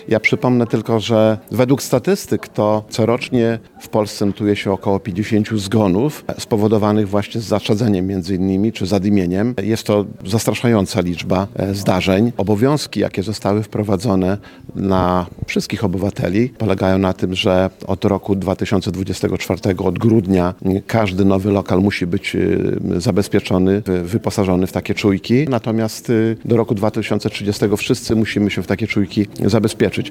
Starosta Lech Szabłowski podkreślił, że obowiązkiem samorządowców jest dbanie o bezpieczeństwo mieszkańców.